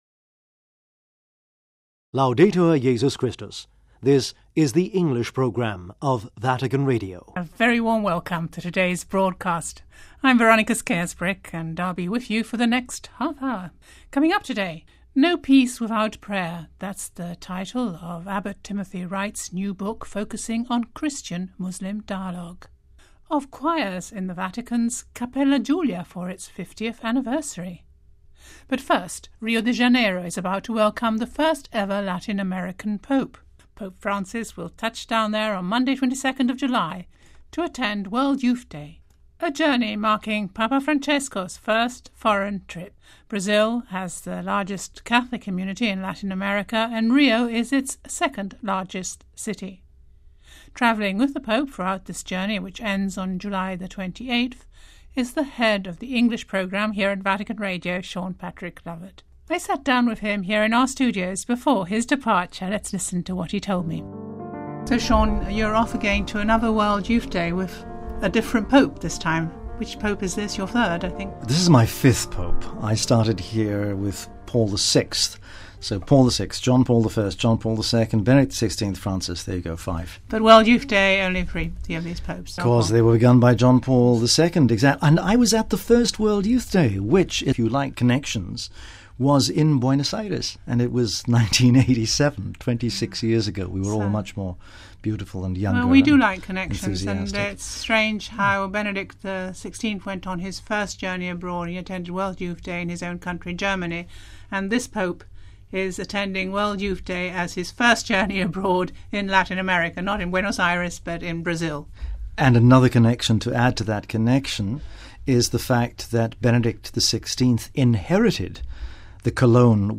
Looking ahead to World Youth Day : We bring you an interview